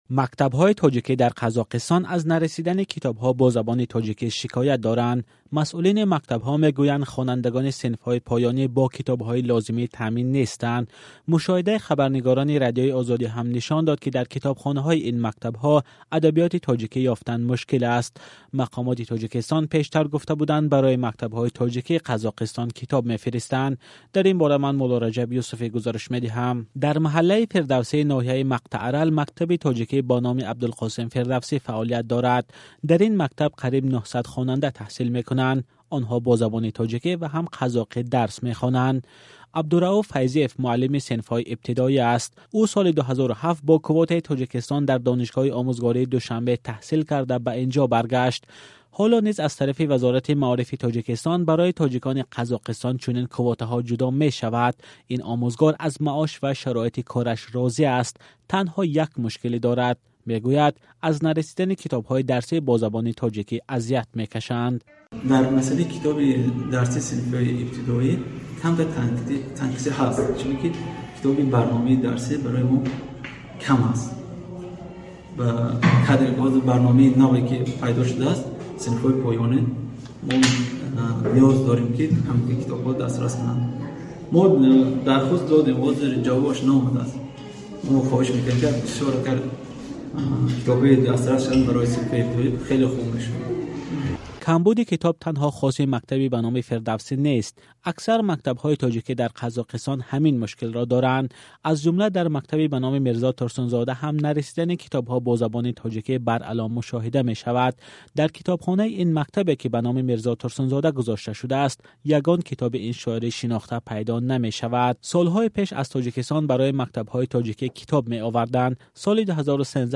Гузоришҳои радиоӣ